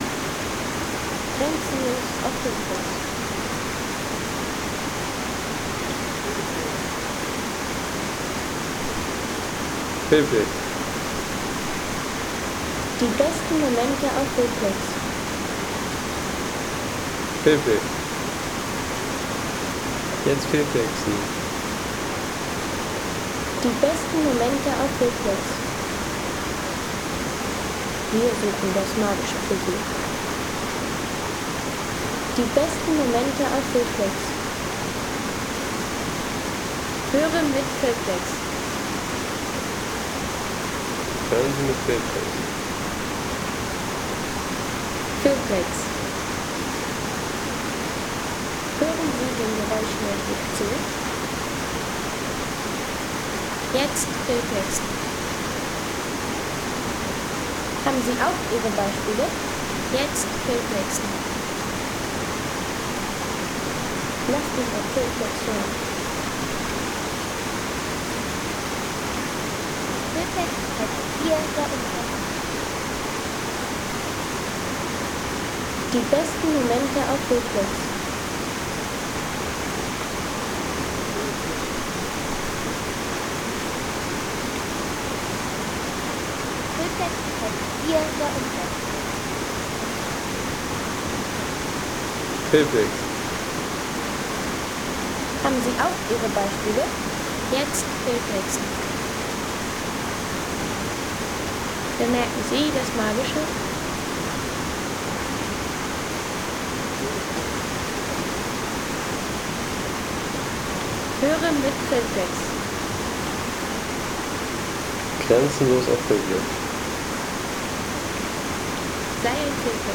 Wildwasserfälle Reutte | Alpine Wasserfallkulisse
Authentische Wasserfall-Atmosphäre aus Reutte in Österreich.
Ein lebendiger Wasserfall-Sound aus Reutte für Filme, Reisevideos, Dokumentationen und atmosphärisches Storytelling.